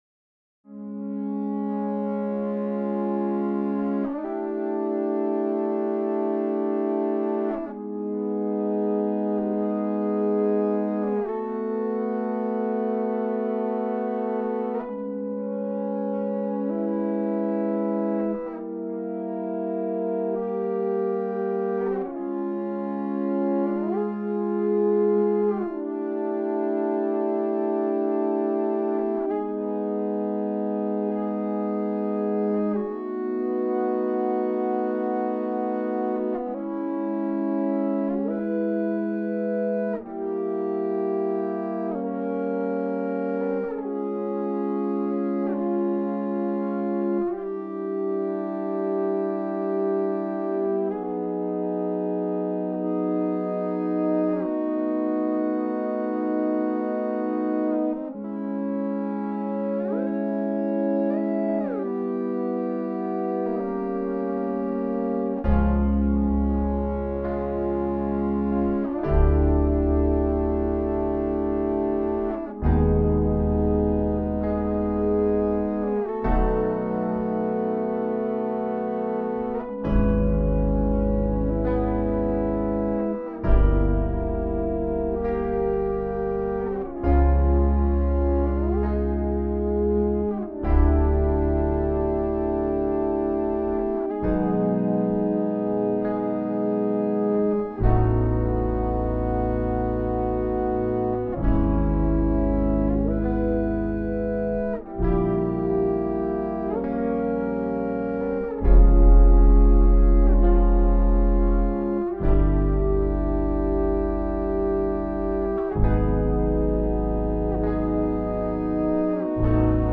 6/4, noire = 100.
[0'00] 1er tour avec les e-bows
[1'05] 2ème tour où se rajoute la basse et les 6 parties "notes"
[2'10] 3ème tour avec le thème façon "Mandoline"
[3'14] 4ème tour où se rajoute les 2 thèmes "Fuzz" en plus
[4'19] accord de fin
Ce morceau est en 4 cycles de 6 mesures (que l'on peut sentir en 4+2).